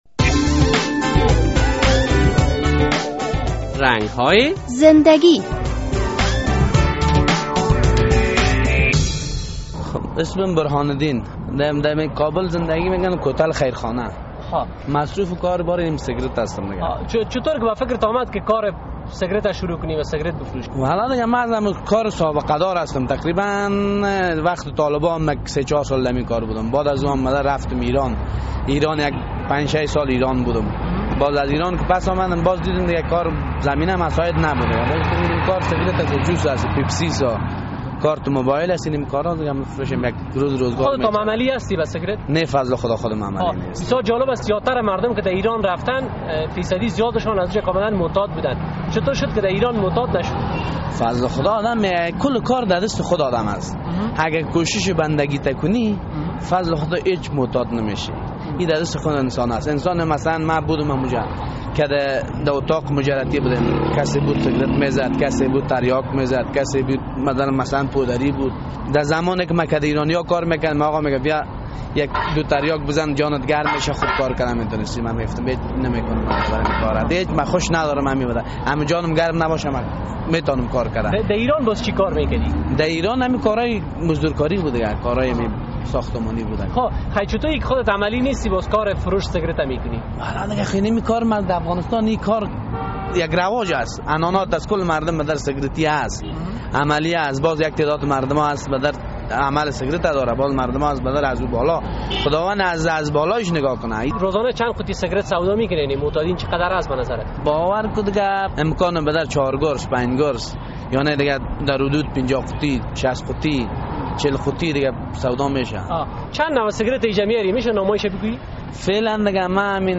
در این برنامهء رنگ های زندگی با یک تن از باشنده های کابل صحبت شده است.